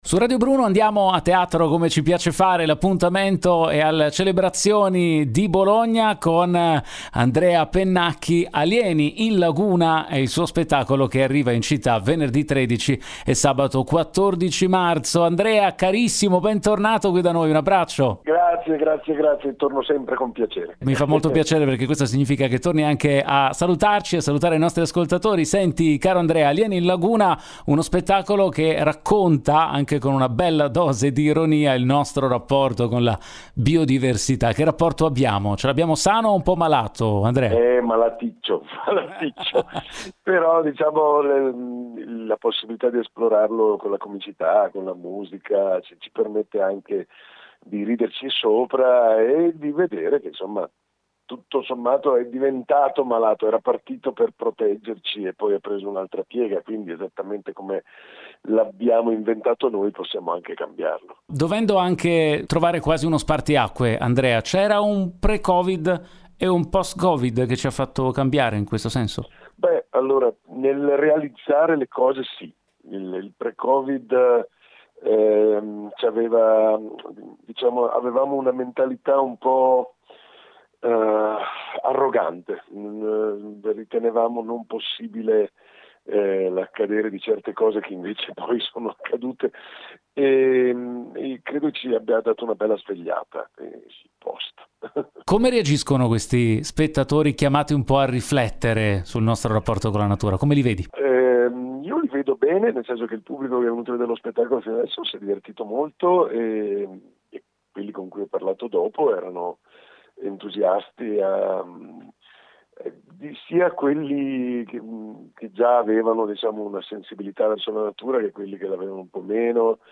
Home Magazine Interviste Andrea Pennacchi a Bologna con “Alieni in laguna”